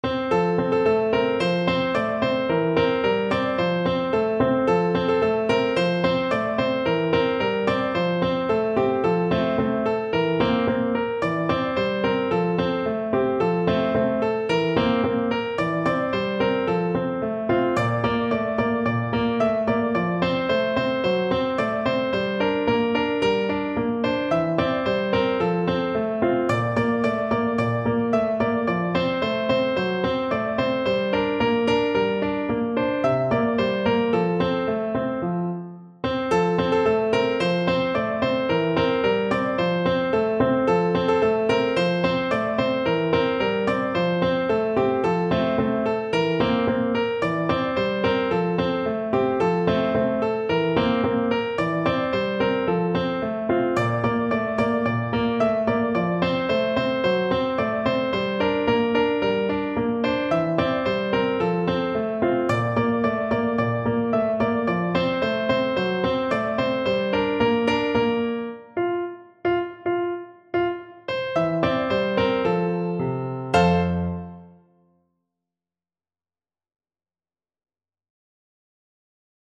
Free Sheet music for Piano
No parts available for this pieces as it is for solo piano.
F major (Sounding Pitch) (View more F major Music for Piano )
Allegro =c.110 (View more music marked Allegro)
2/4 (View more 2/4 Music)
Piano  (View more Easy Piano Music)
Classical (View more Classical Piano Music)
dva_bracanina_PNO.mp3